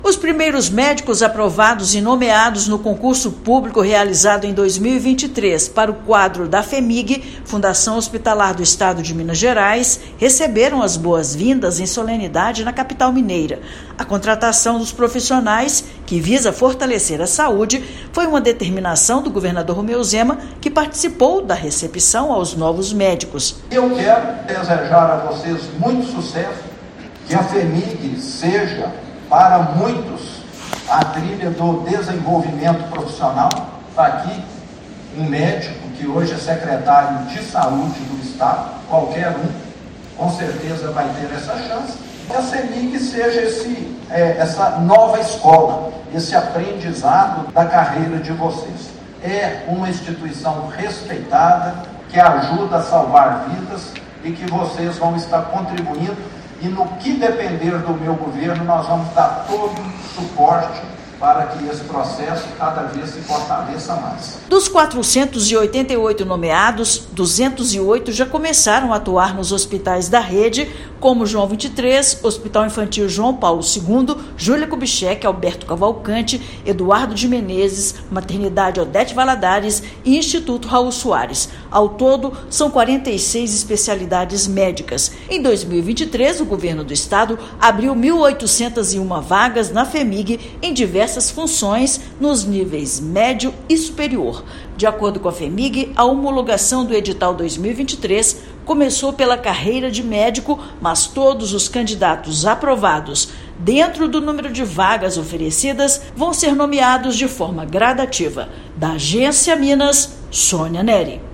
Mais de 200 profissionais já estão atuando nos hospitais da rede estadual, fortalecendo o atendimento dos pacientes do SUS. Ouça matéria de rádio.